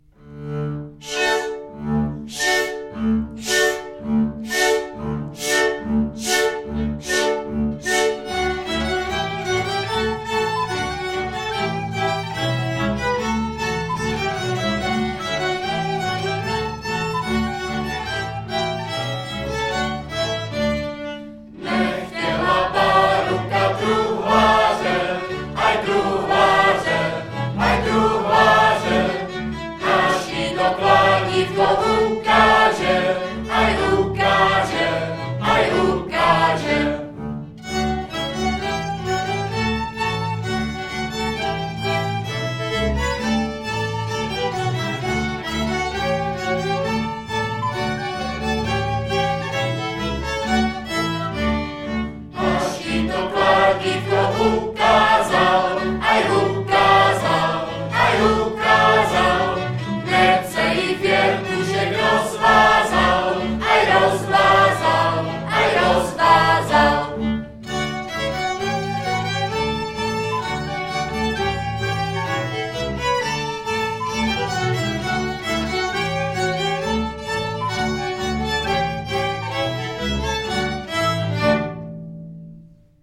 Nahrávali jsme ve Spálově v ZUŠce na jaře 2019.